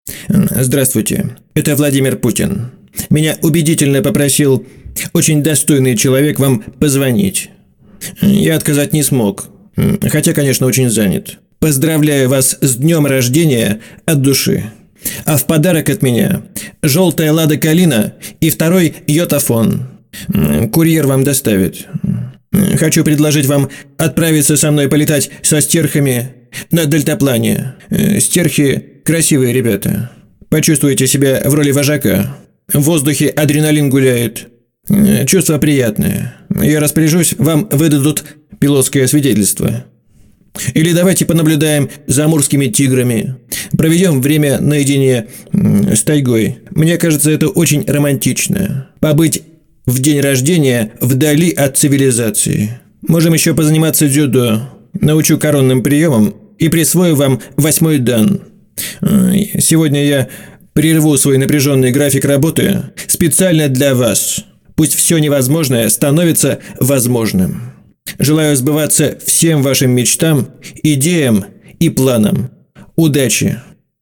от ПутинаЗабавное и доброе аудио поздравление с днем рождения голосом Путина, которое обязательно поднимет настроение вашего адресата, надолго запомнится ему, вызовет шутки и напомнит о некоторых событиях, связанных с известным политиком, которые вызывали и улыбку и восхищение одновременно.
Поздравление голосом В.Путина с днем рождения по телефону напоминает о том времени и делает это поздравление прикольным и шутливым.